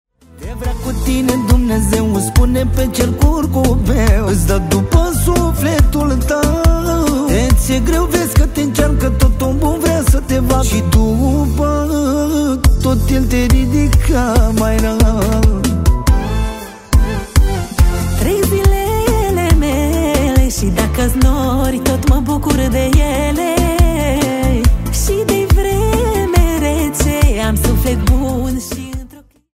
Categorie: Manele